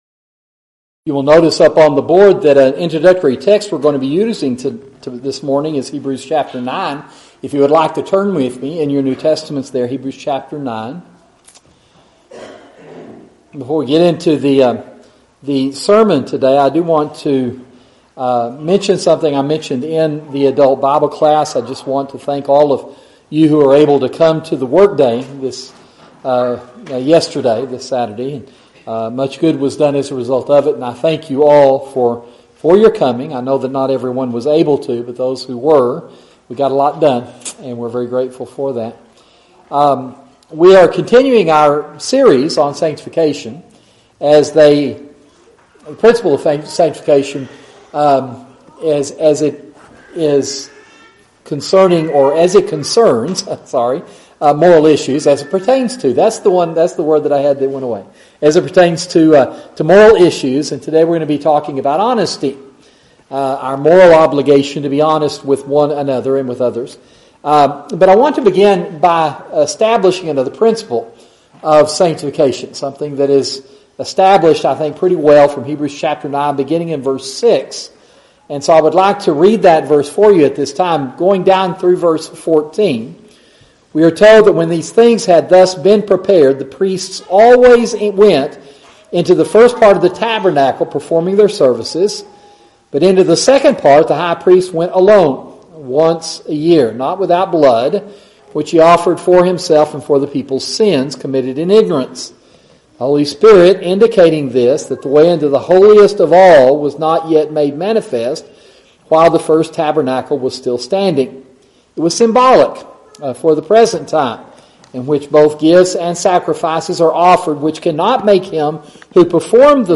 Audio YouTube Video of Sermon <<———><><———>> Share this: Tweet